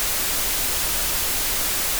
Compressed Gas Leak SFX
compressed_gas_leak_0.ogg